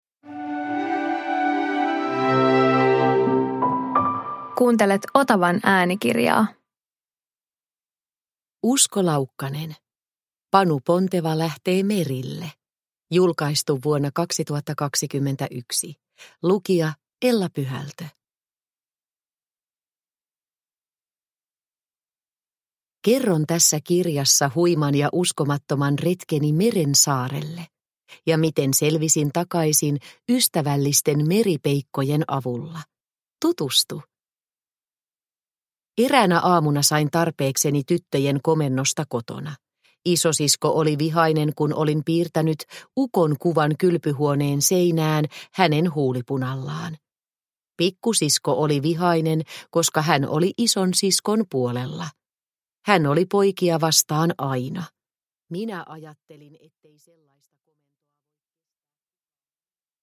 Panu Ponteva lähtee merille – Ljudbok – Laddas ner